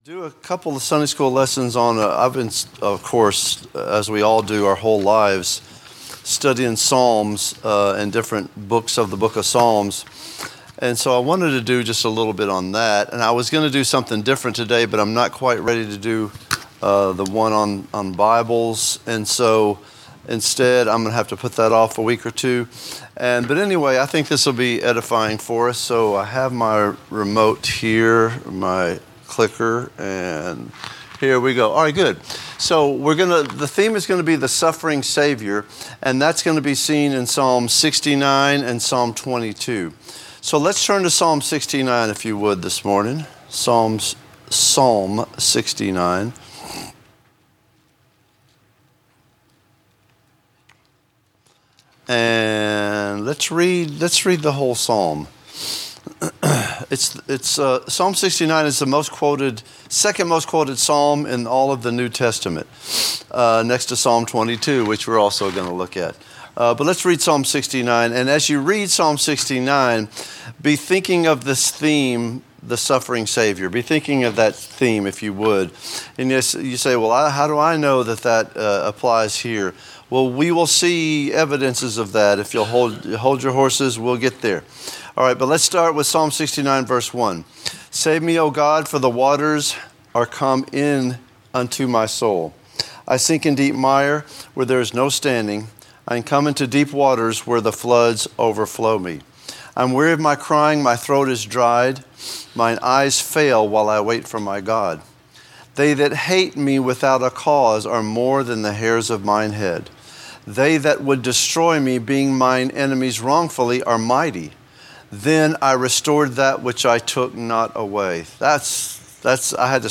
A message from the series "The Suffering Saviour."